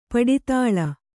♪ paḍi tāḷa